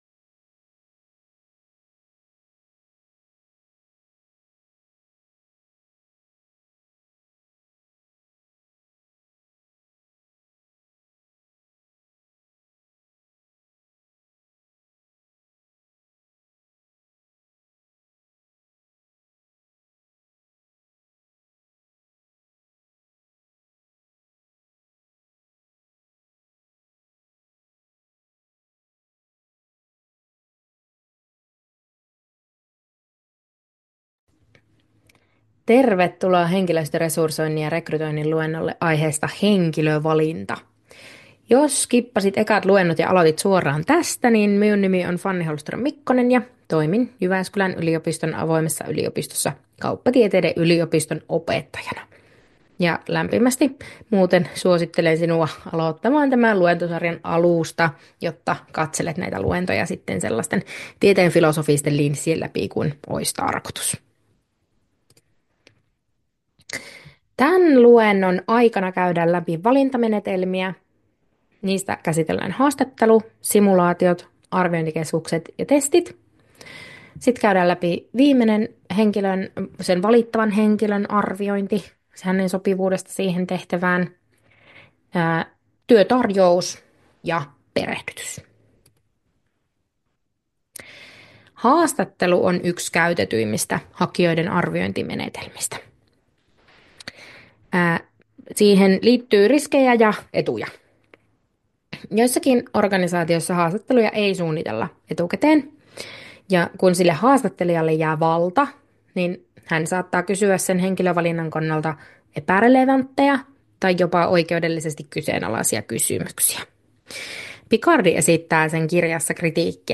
YJOA2220 Henkilöstöresursointi ja rekrytointi, luentotallenne aiheesta henkilövalinta.